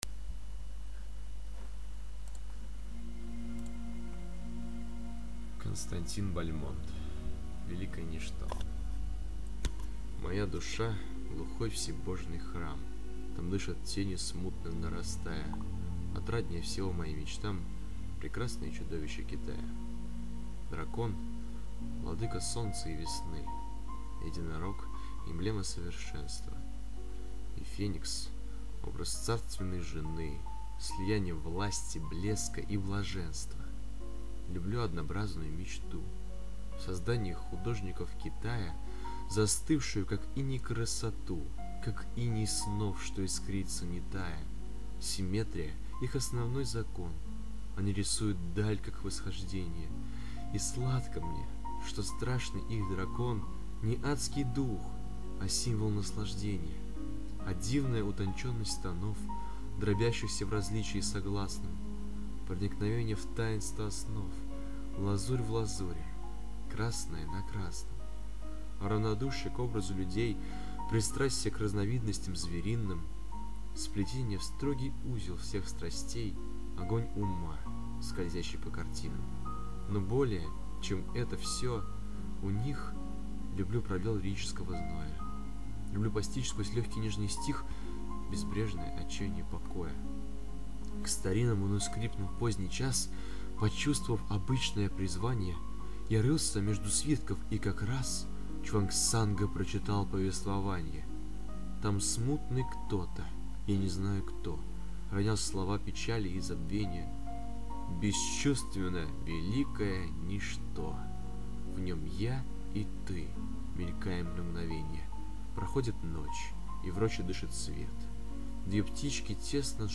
Песни на стихотворение: